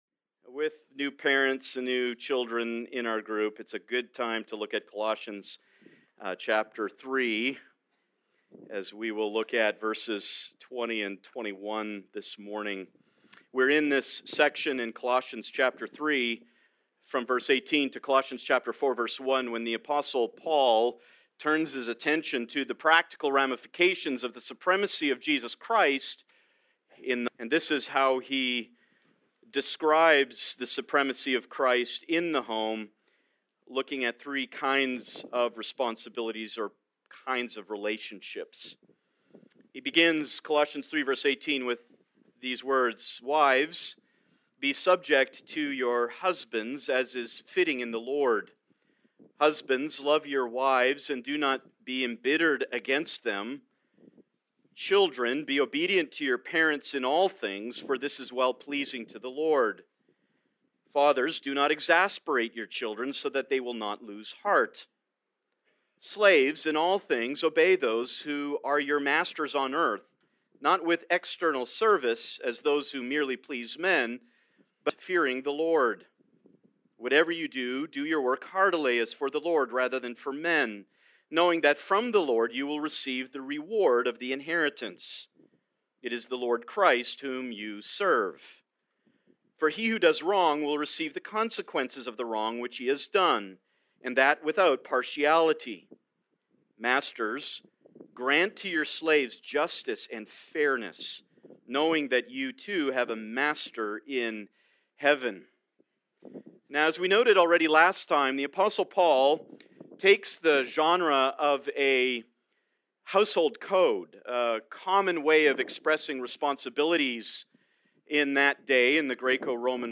Please note that, due to technical difficulties, this recording skips brief portions of audio.